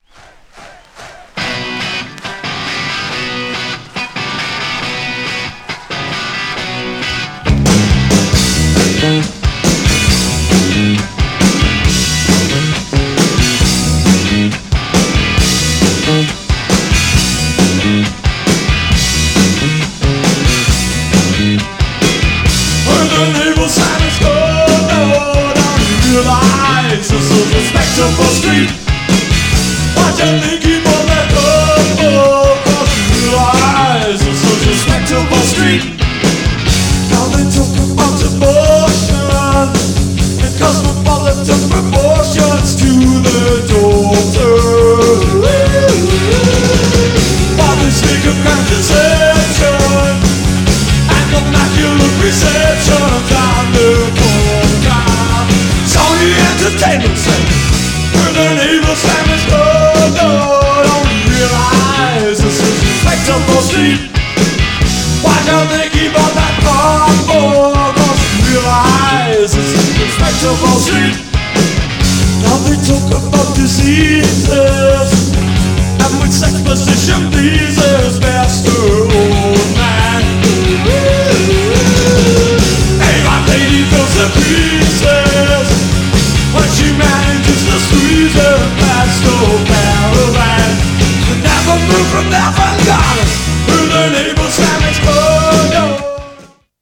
GENRE Dance Classic
BPM 166〜170BPM